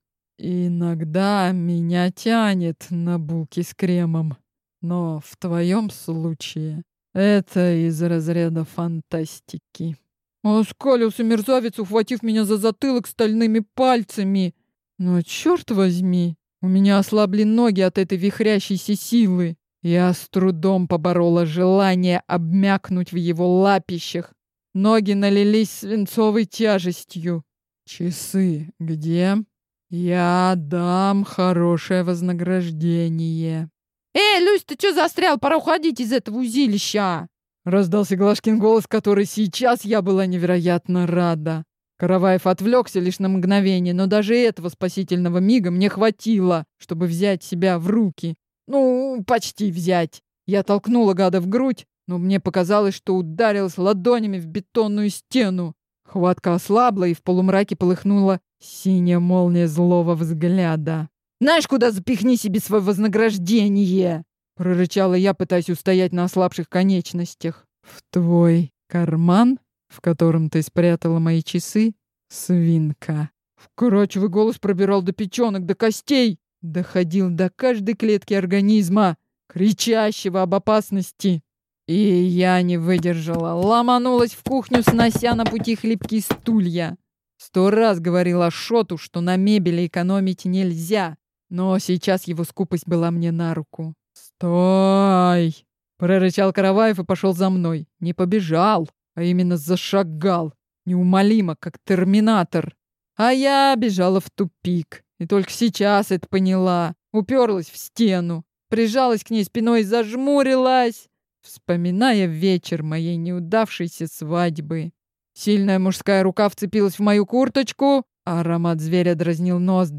Аудиокнига Кинг сайз. Как украсть миллиардера | Библиотека аудиокниг